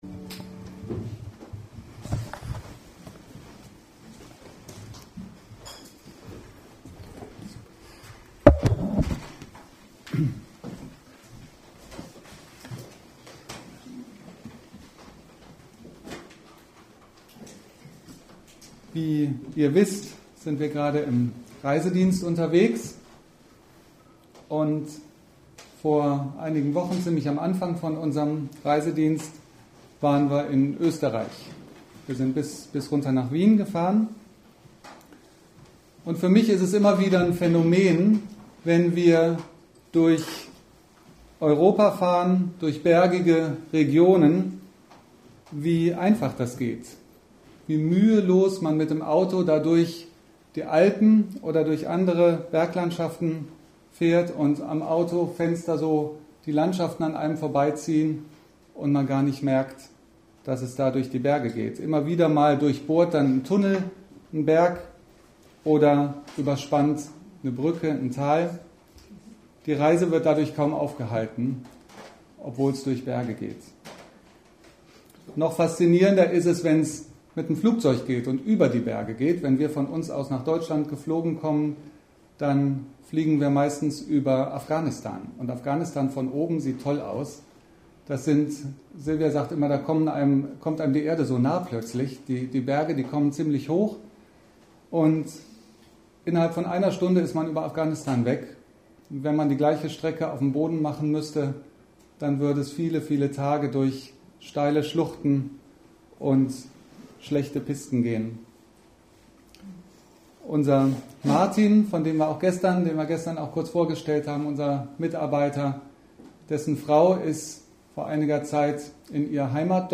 Sonntagspredigt in der EfG Bonn